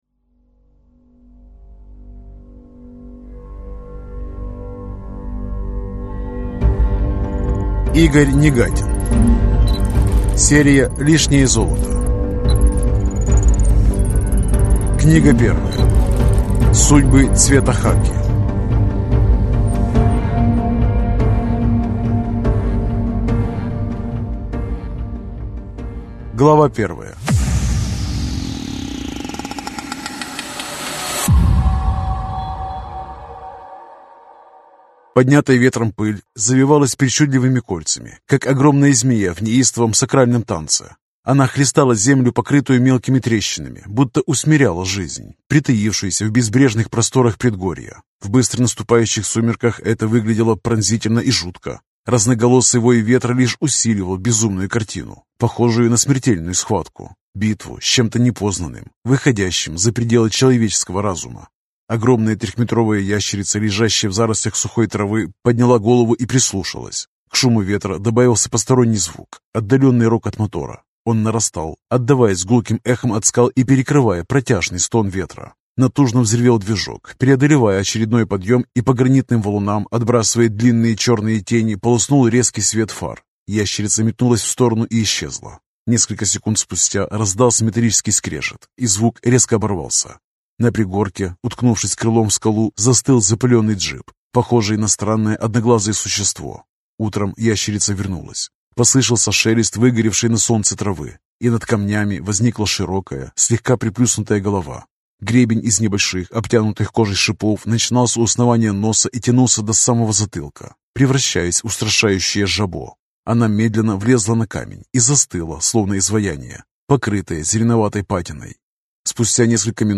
Аудиокнига Лишнее золото. Судьбы цвета хаки | Библиотека аудиокниг